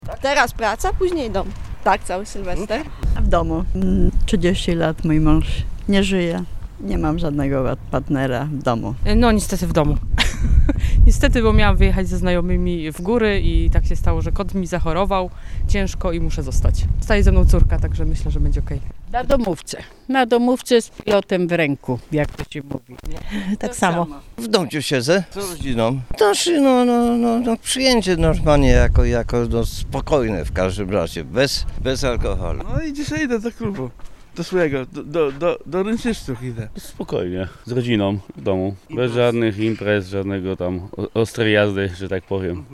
Na kilka godzin przed sylwestrowymi szaleństwami spytaliśmy zielonogórzan gdzie będą bawili się podczas tej szczególnej nocy: